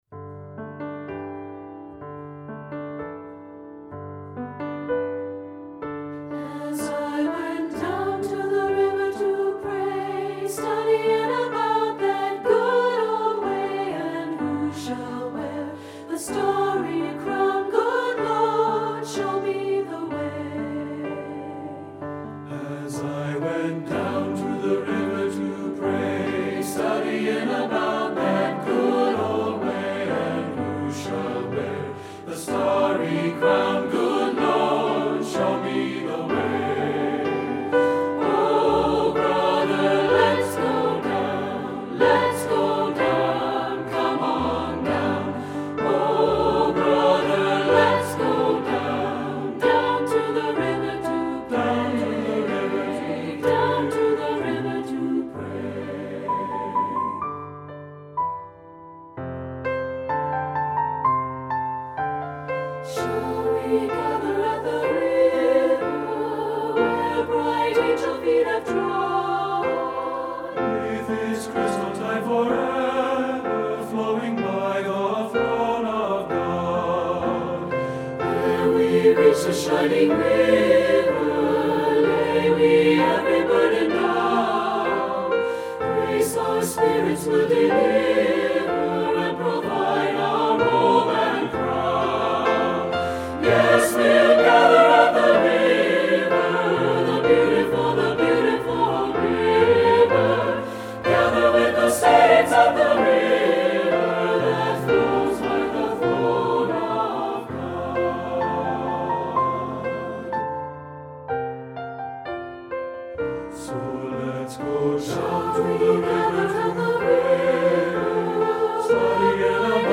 Voicing: SATB-opSAB